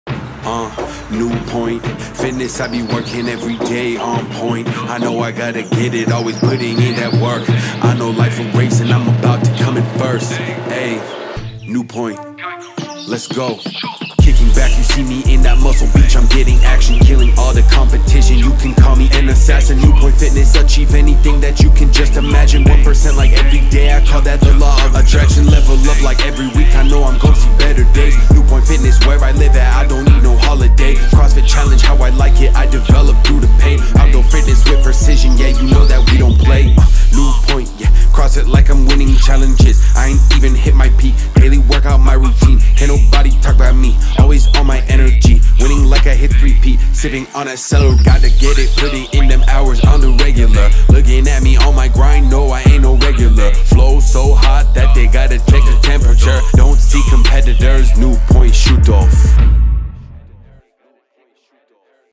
RAP UND BEAT BEISPIELE